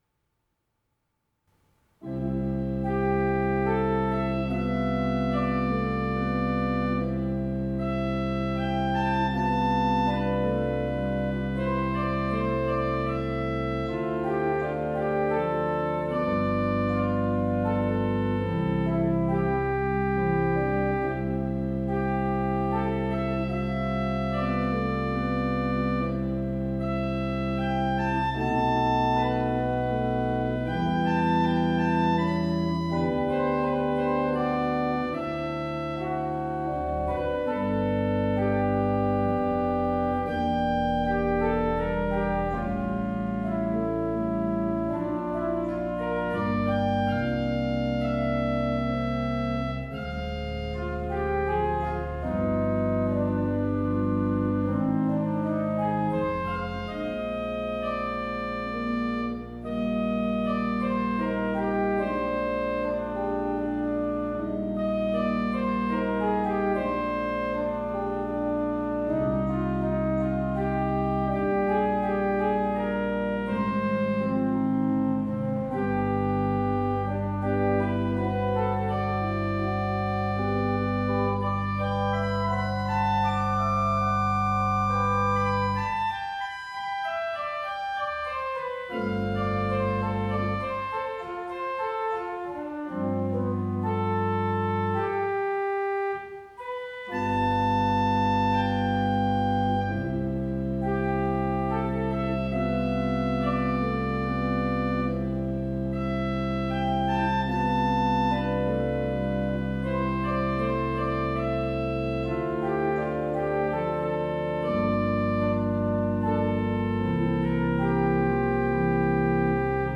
Orgel
Unsere Orgel (Orgel-Mayer, Heusweiler) wurde im Sommer 2012 durch die Orgelbaufirma Förster & Nicolaus (Lich) gründlich gereinigt und repariert und hat einen vollen und harmonischen Klang.